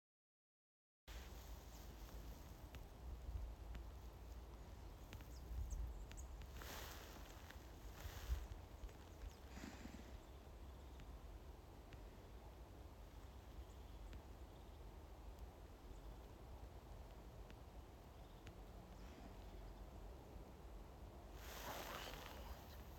хохлатая синица, Lophophanes cristatus
Administratīvā teritorijaĀdažu novads
СтатусСлышен голос, крики
ПримечанияKopā dzirdēts ar cekulzīlītes saucieniem